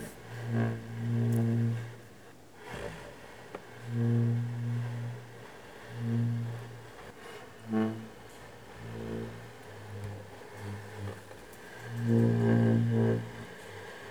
ajout des sons enregistrés à l'afk
environnement_03.wav